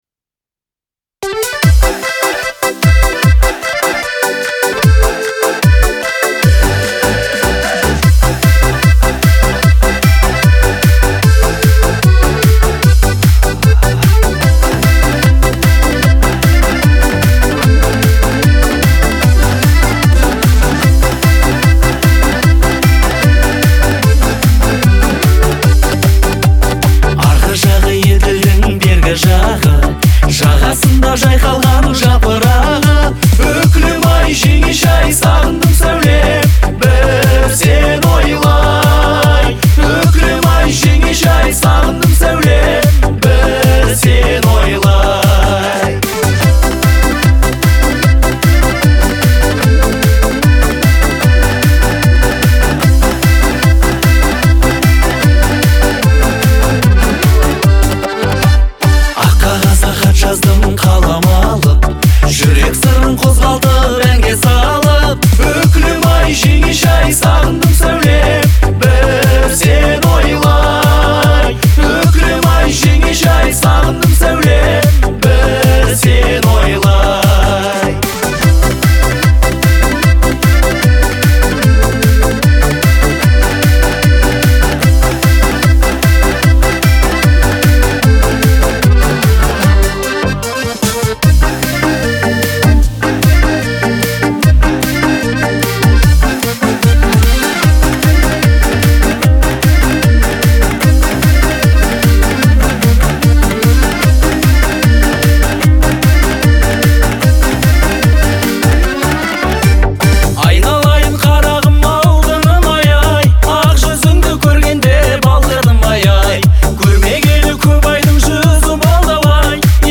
это яркое музыкальное произведение в жанре поп-фолк